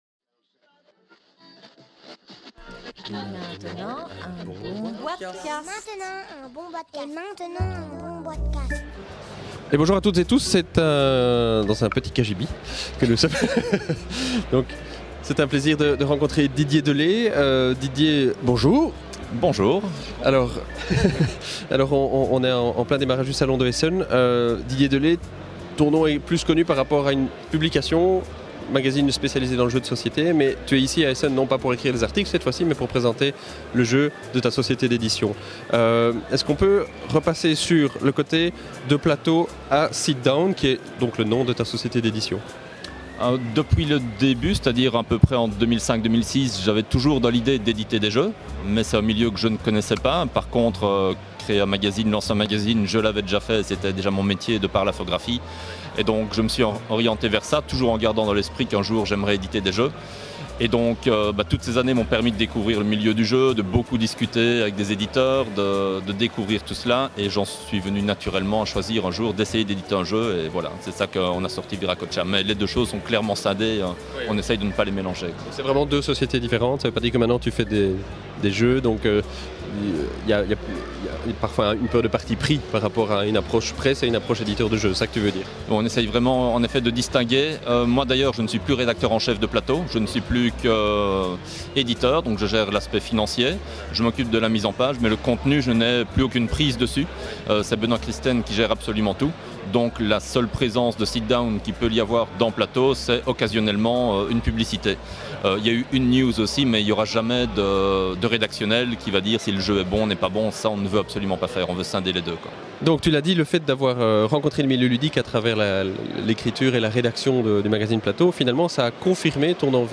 enregistré au Salon international du Jeu de Société de Essen – Octobre 2011